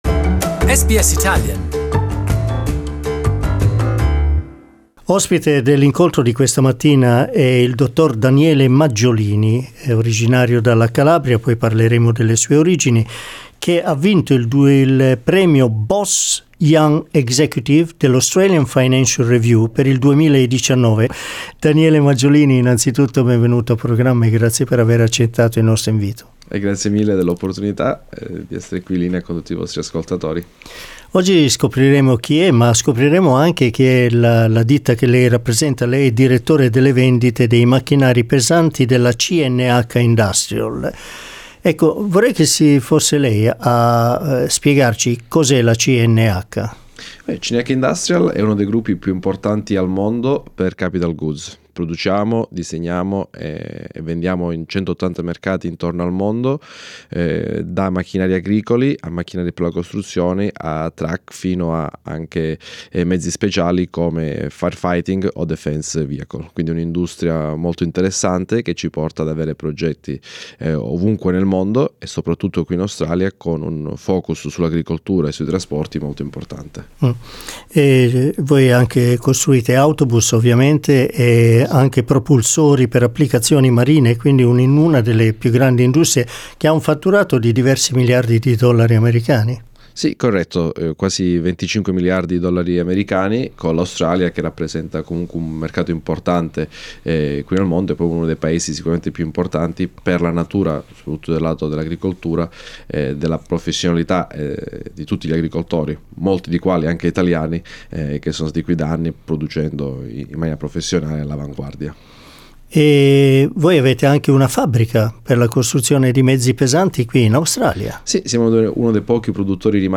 In this interview to SBS Italian he explains what it takes to be a successful young manager and his vision for the future of heavy machinery and truck industry in Australia.